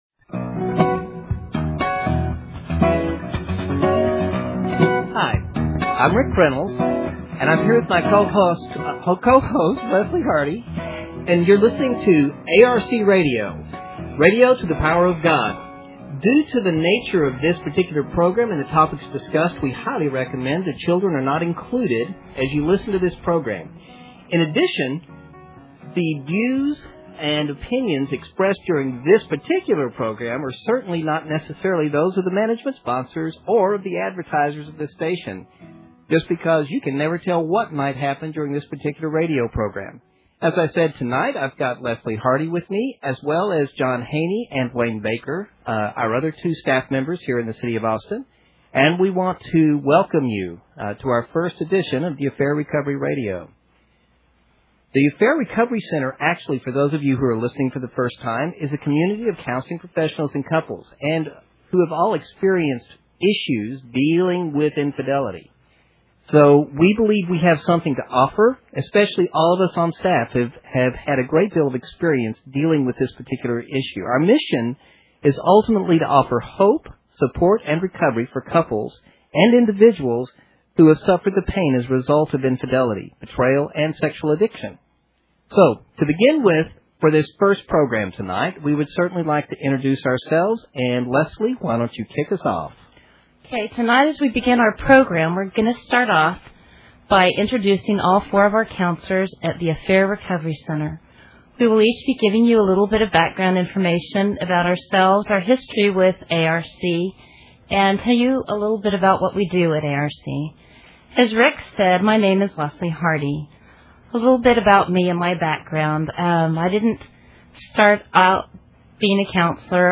The staff introduce themselves and describe the online and offline services of the ARC.